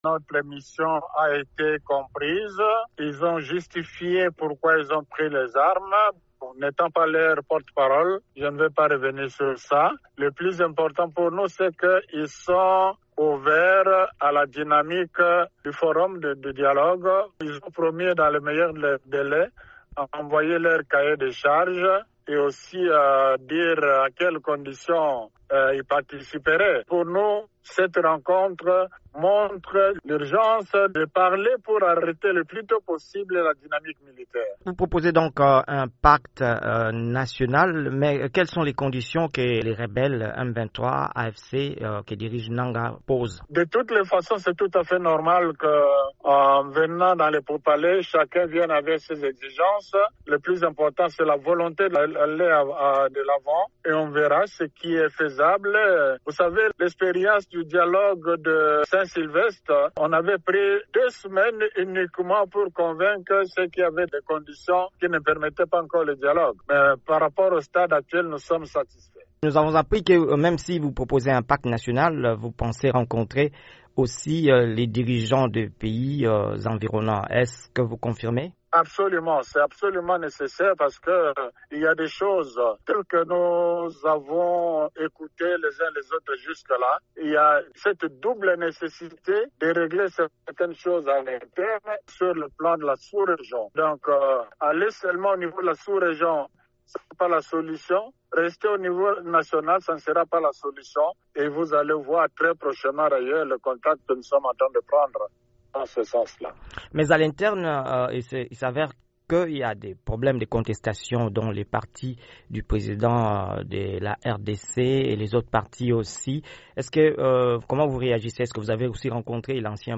a interviewé